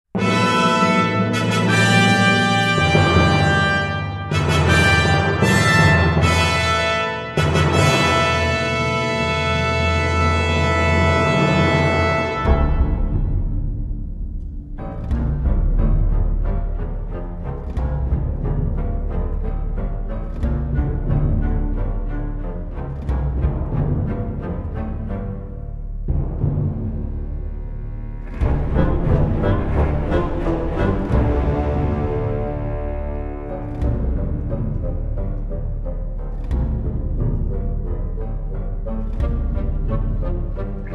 Arabic vocals and Shawm